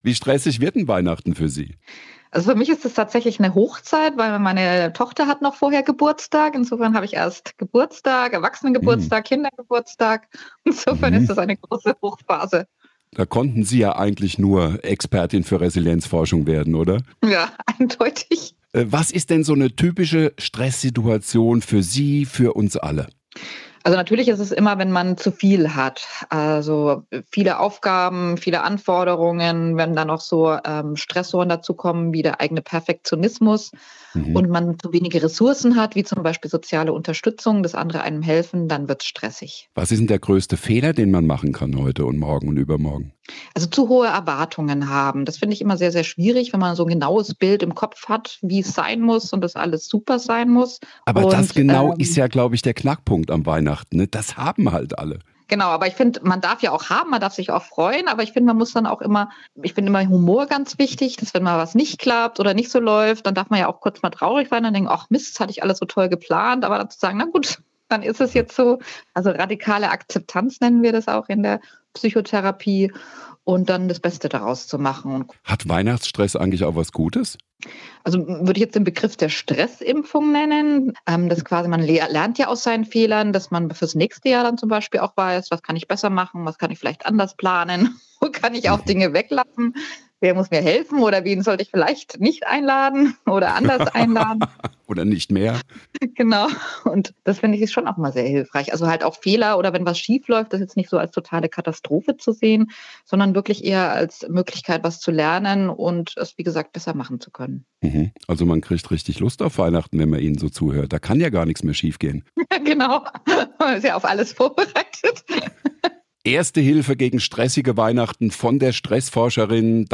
SWR1 Interviews